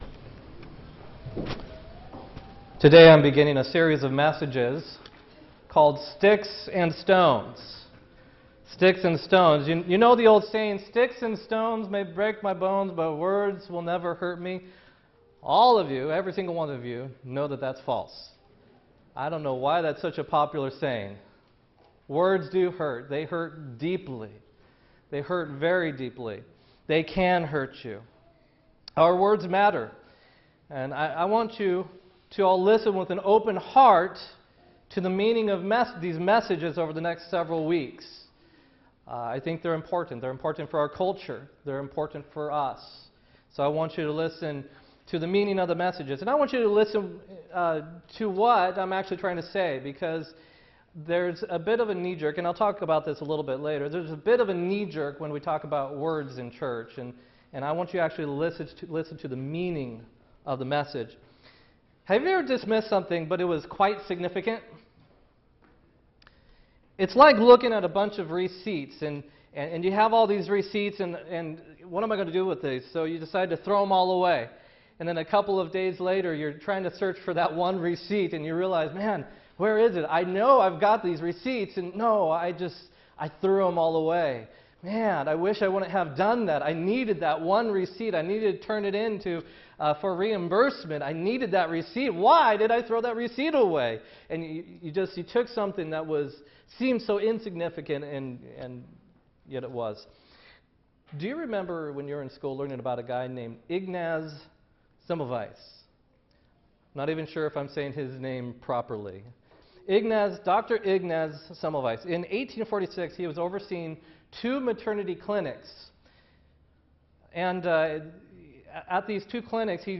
10-15-16-sermon